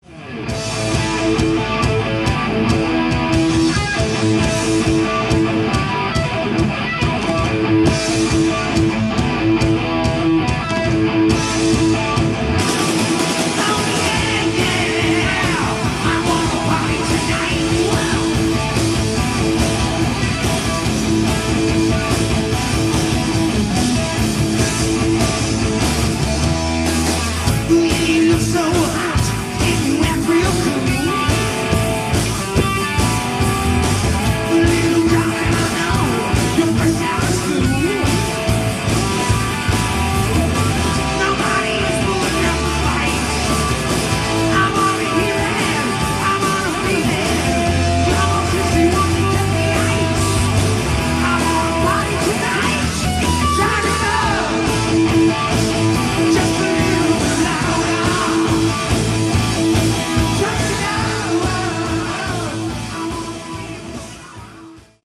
Category: Hard Rock
lead guitar, backing vocals
lead vocals
bass, backing vocals
drums, backing vocals
live 4/4/87